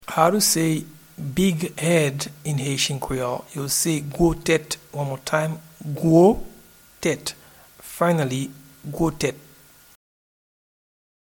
Pronunciation and Transcript:
Big-head-in-Haitian-Creole-Gwo-tet.mp3